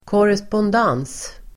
Ladda ner uttalet
korrespondens substantiv, correspondence Uttal: [kårespånd'en:s (el. -'ang:s)] Böjningar: korrespondensen Synonymer: brevväxling Definition: brevväxling Sammansättningar: korrespondens|kurs (correspondence course)